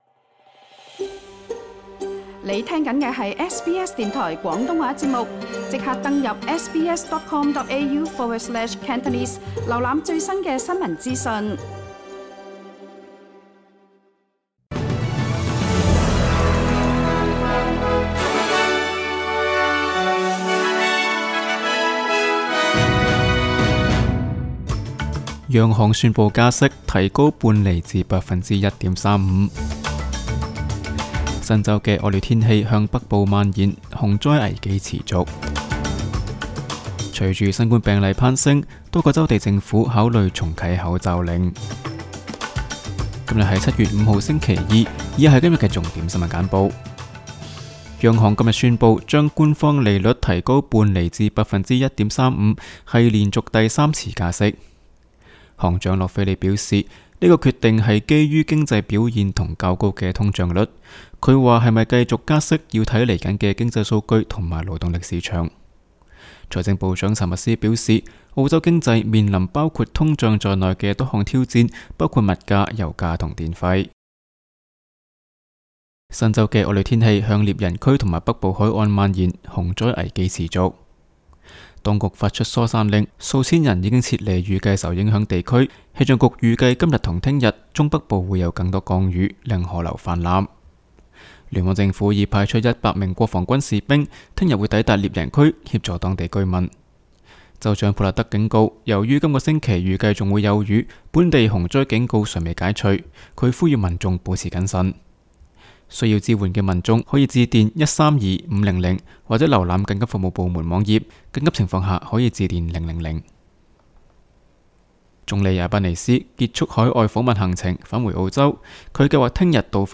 SBS 新聞簡報（7月5日）
SBS 廣東話節目新聞簡報 Source: SBS Cantonese